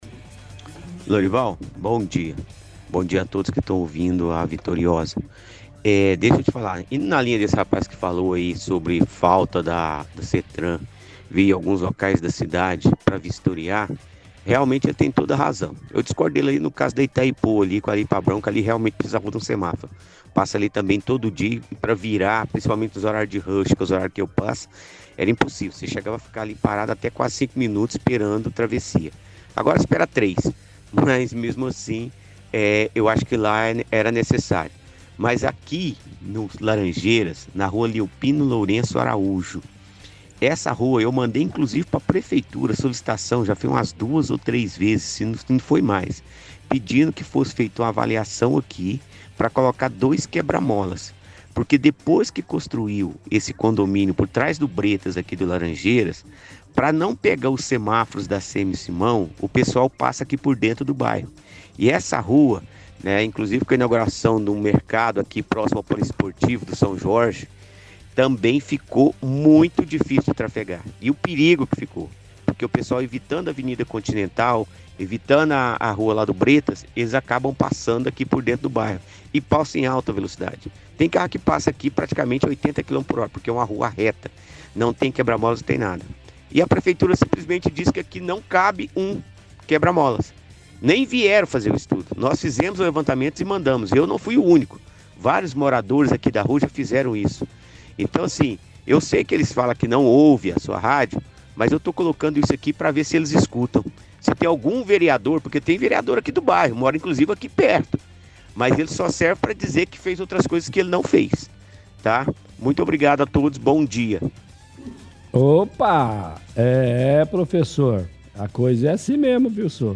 – Outro ouvinte reforça a mesma reclamação sobre a SETTRAN, fala do bairro laranjeiras, que mandou solicitação pra prefeitura para avaliarem a instalação de quebra-molas, bairro muito movimentado e perigoso porque motoristas evitam avenidas e preferem passar por dentro do bairro. Diz que prefeitura respondeu só dizendo que não tem necessidade de instalar quebra-molas, mas que nem foram lá avaliaram.